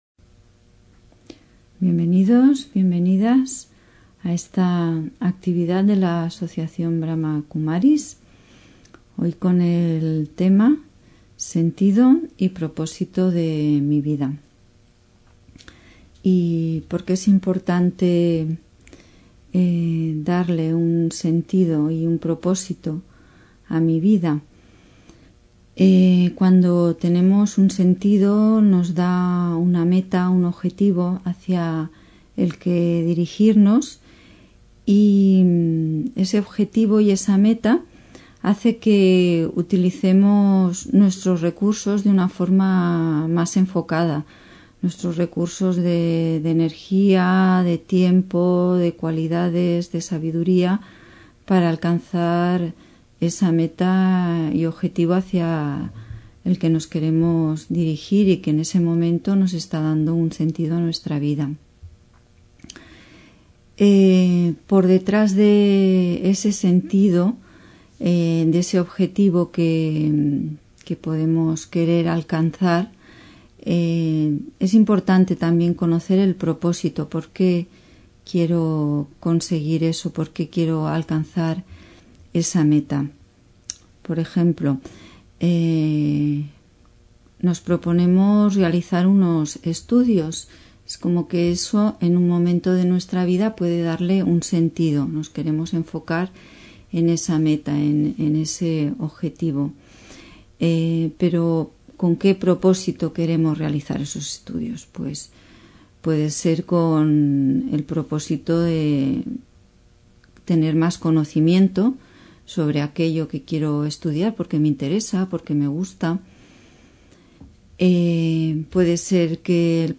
Meditación y conferencia: Sentido y propósito de mi vida (23 Septiembre 2024)